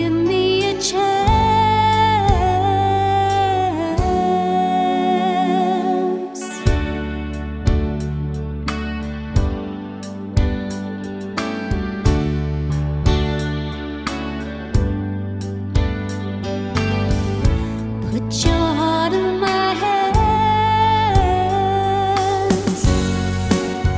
With Girl Rock 6:13 Buy £1.50